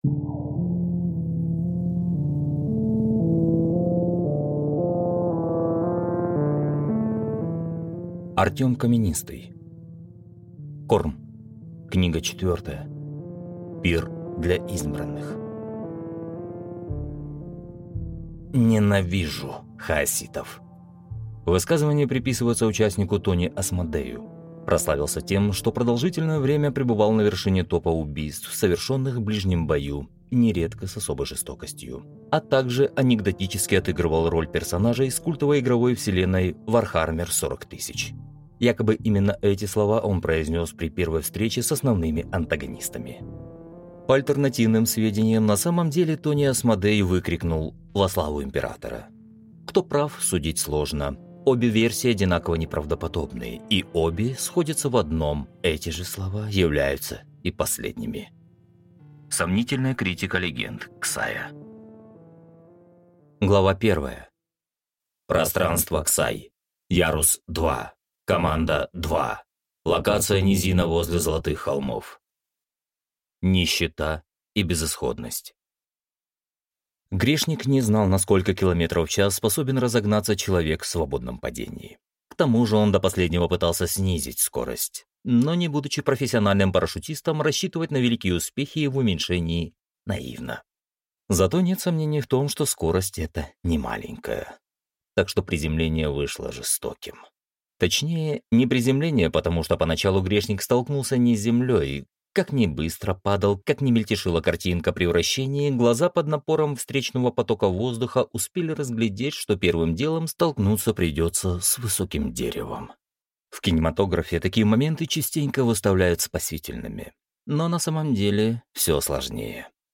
Аудиокнига Пир для избранных | Библиотека аудиокниг